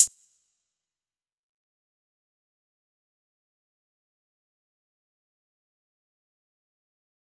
Closed Hats
DMV3_Hi Hat 10.wav